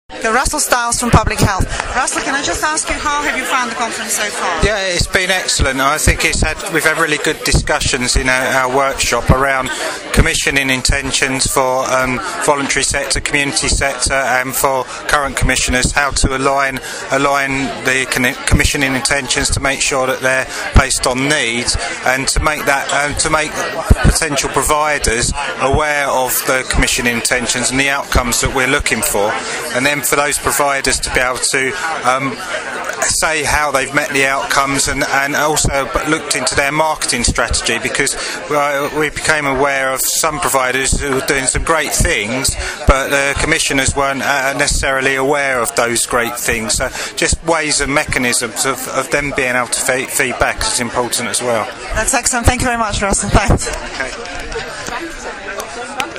An interview
at Kingston Voluntary Action's Health Conference in January 2013